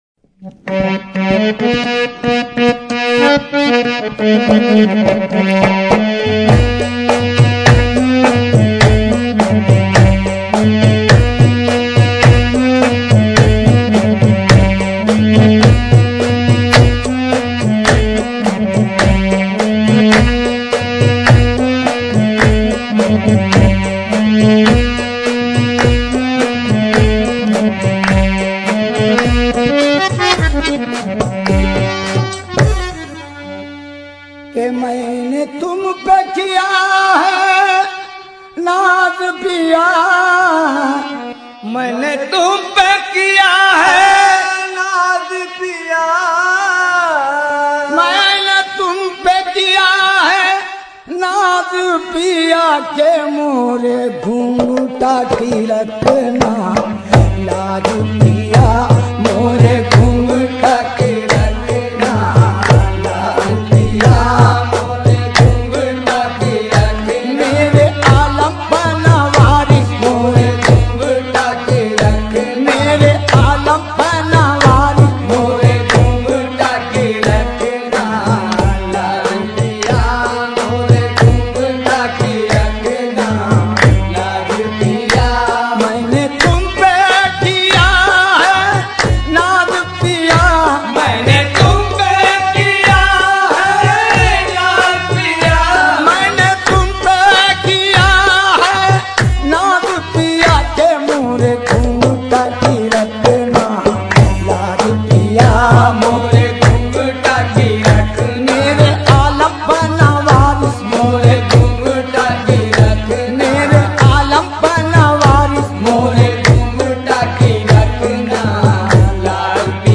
Islamic Qawwalies And Naats > Dargahon Ki Qawwaliyan